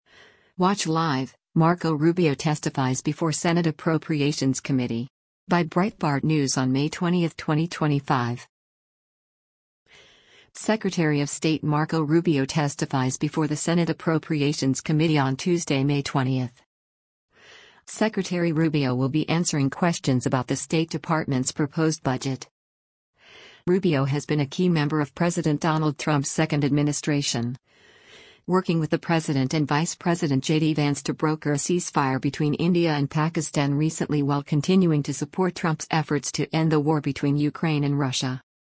Secretary of State Marco Rubio testifies before the Senate Appropriations Committee on Tuesday, May 20.